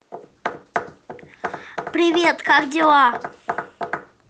vad-hello-mono-32000.wav